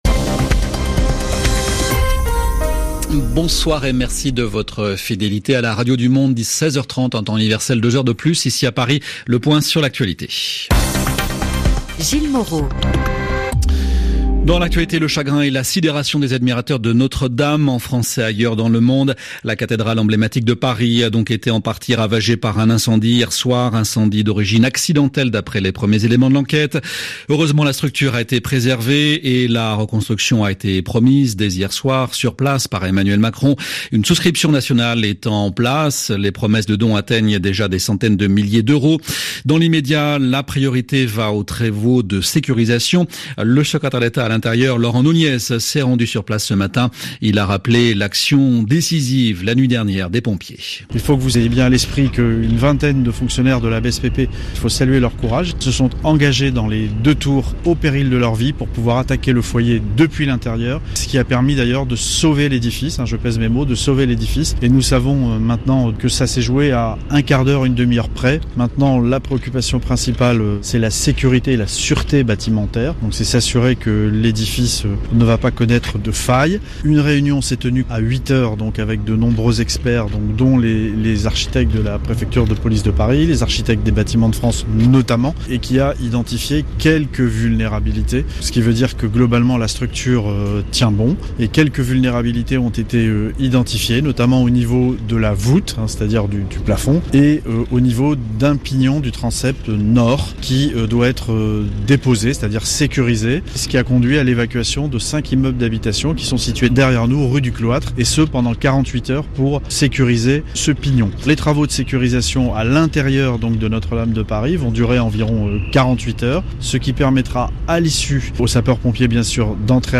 rfi_jrn_dernier_journal.mp3